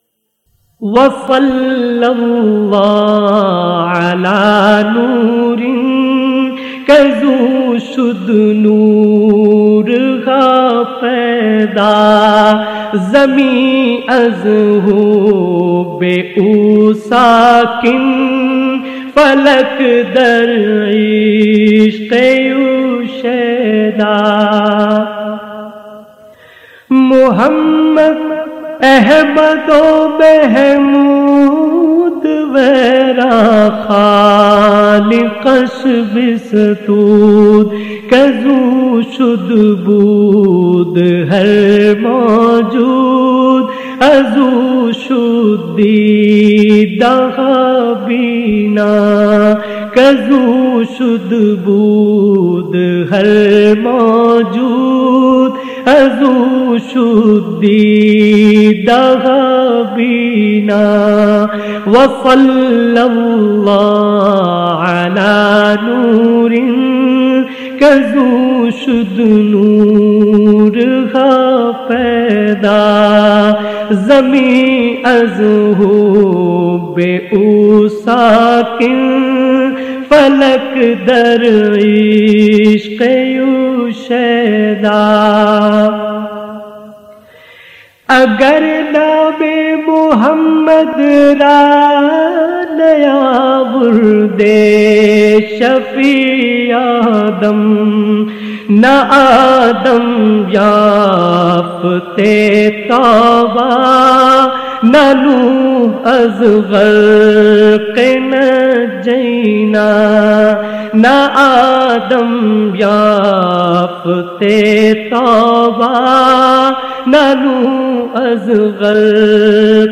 نعت رسول مقبول (ص) : وسلم وعلی نوری